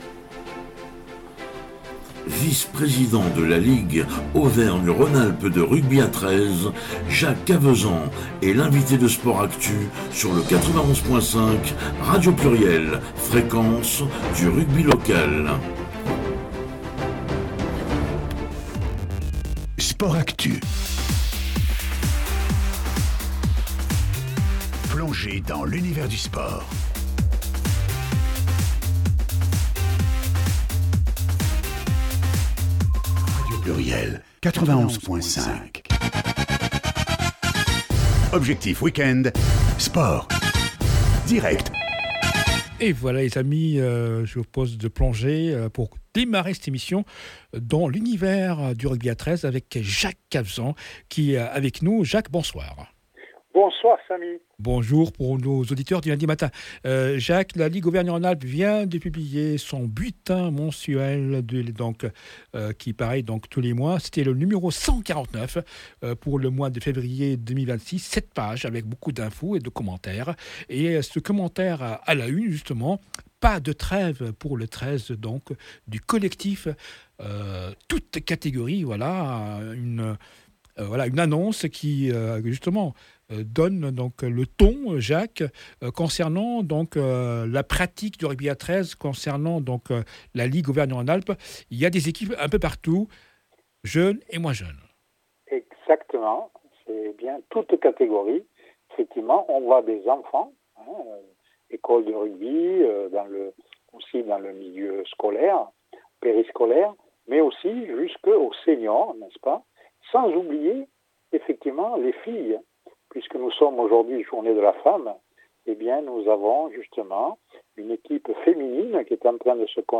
L’interview du président mois de MARS 2026